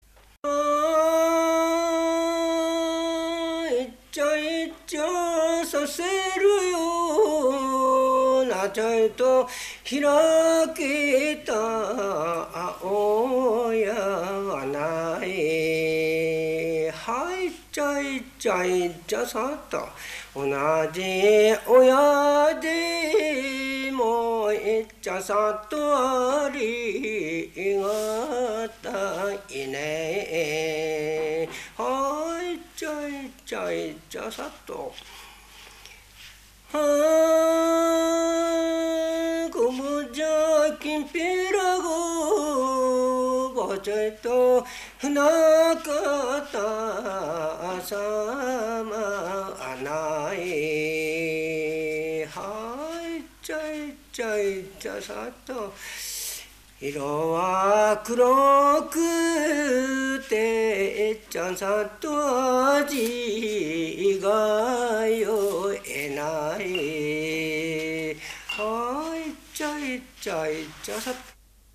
いっちゃいっちゃ節 座興歌